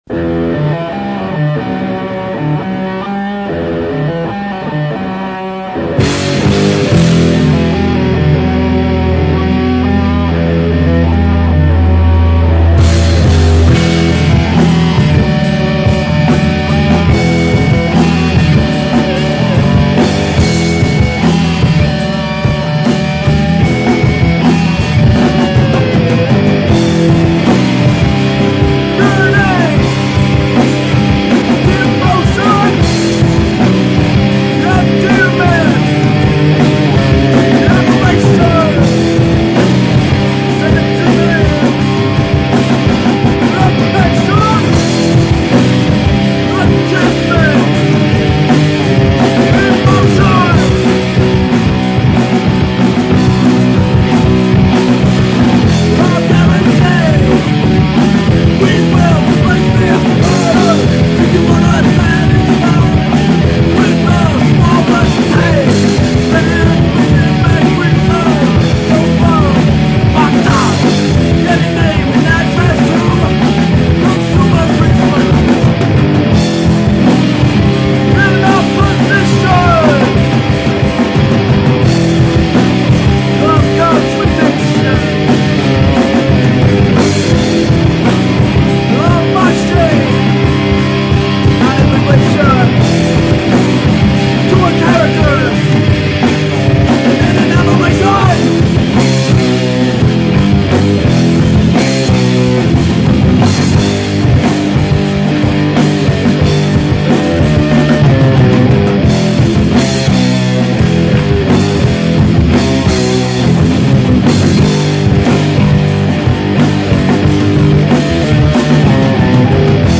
pop punk See all items with this value